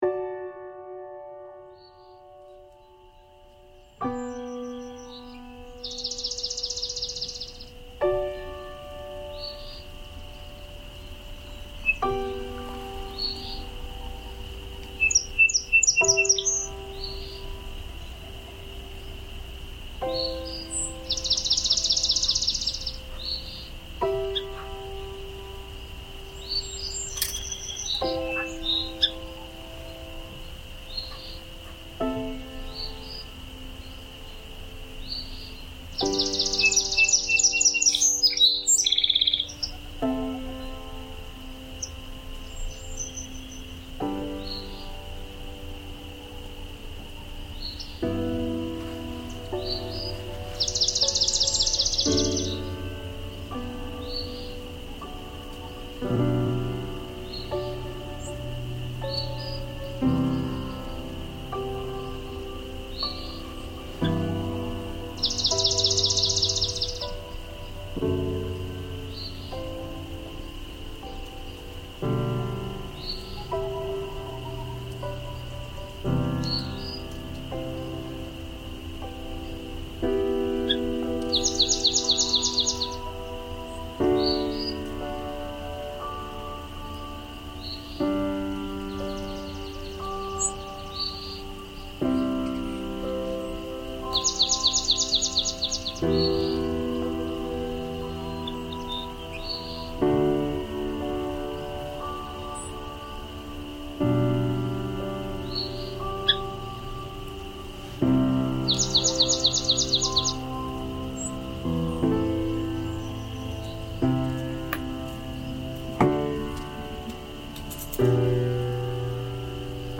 The ghosts of protest. Taksim Meydani soundscape.